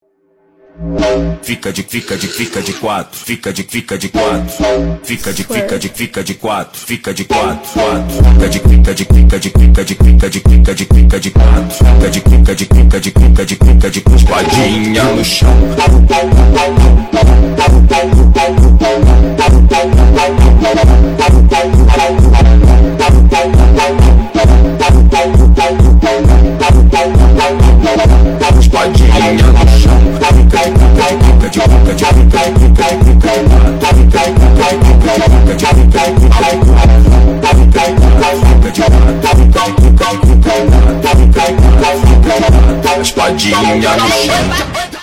Braz Funk